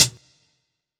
007_Lo-Fi Dark Hi-Hat.wav